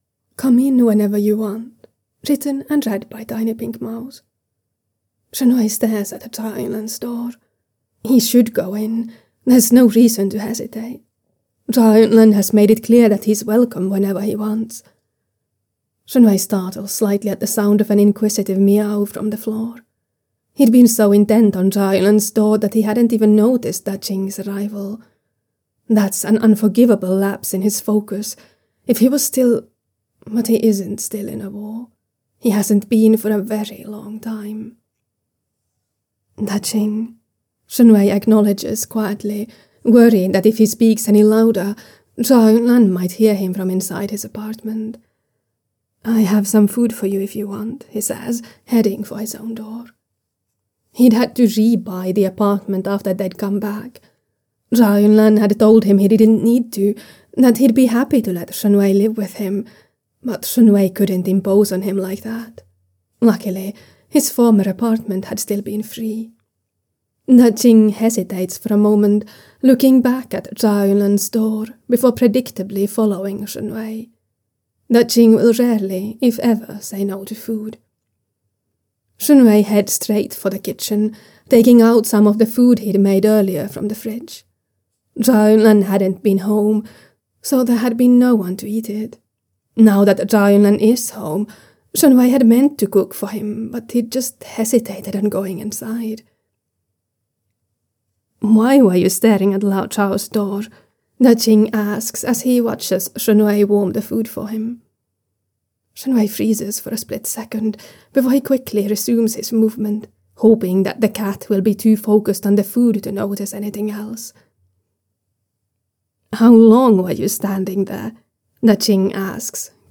Come In Whenever You Want [Podfic